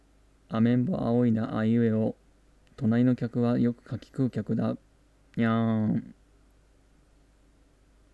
どのあたりが微妙なのかと言うとまずマイクがかなり音質が低いです。
Pimaxマイク